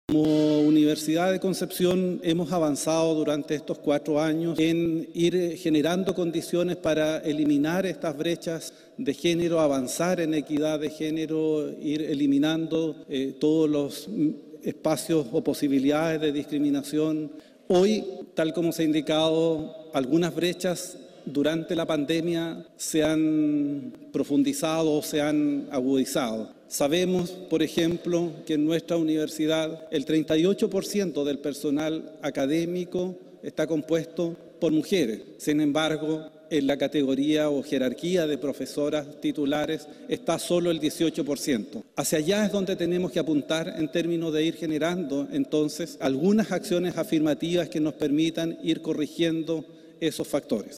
En el lanzamiento de la iniciativa, el rector Carlos Saavedra se refirió a los objetivos trazados.